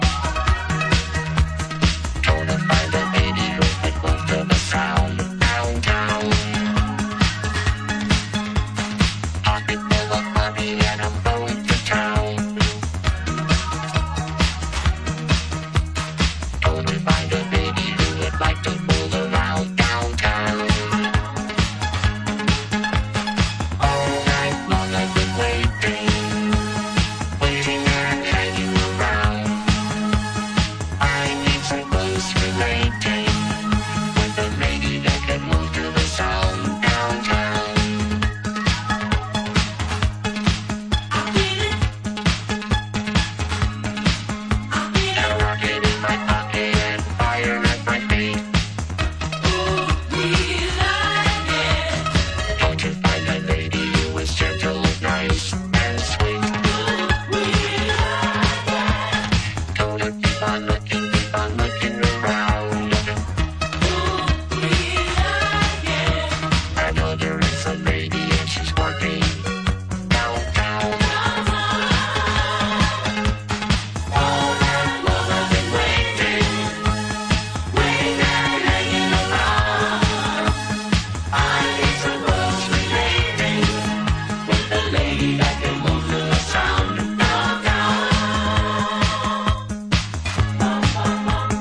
mad disco track
Electro